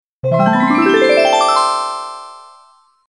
Откройте для себя коллекцию звуков нового уровня — инновационные аудиоэффекты, футуристические мелодии и необычные композиции.